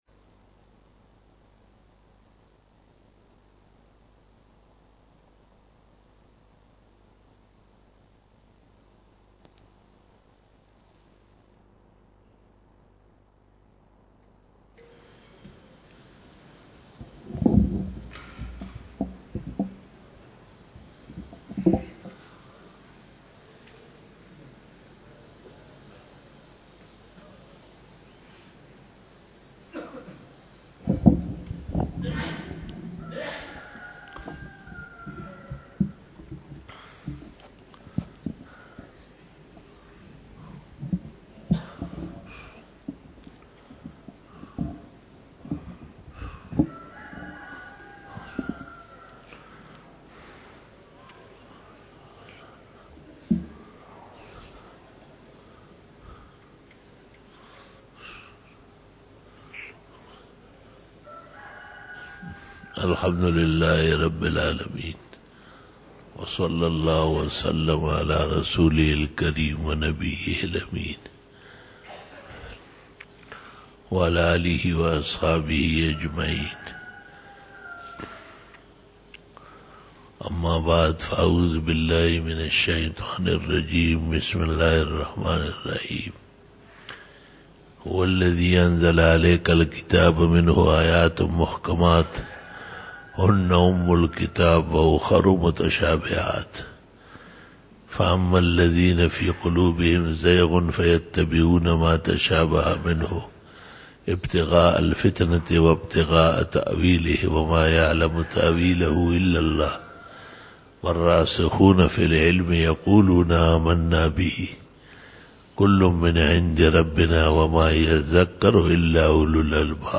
06 BAYAN E JUMA TUL MUBARAK 07 FEBRUARY 2020 (12 Jamadi Us Sani 1441H)
Khitab-e-Jummah